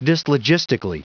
Prononciation du mot dyslogistically en anglais (fichier audio)
Prononciation du mot : dyslogistically